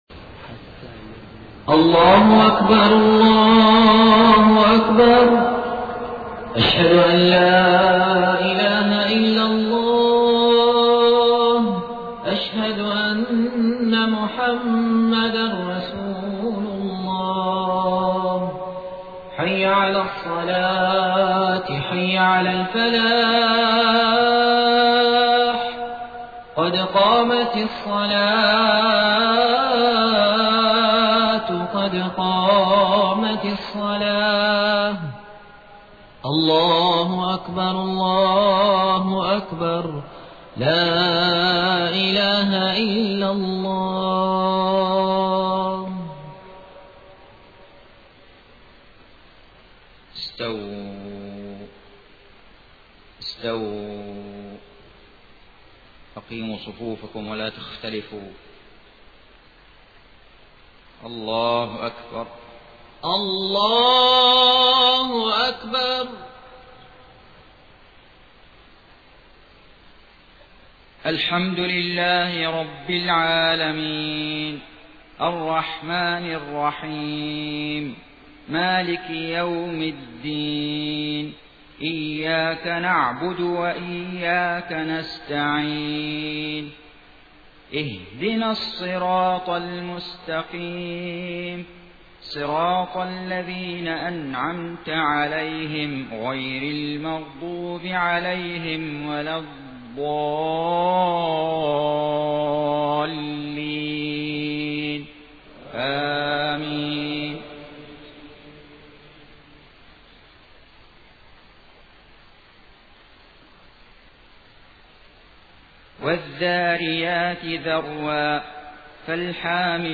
صلاة الفجر 26 صفر 1431هـ سورة الذاريات كاملة > 1431 🕋 > الفروض - تلاوات الحرمين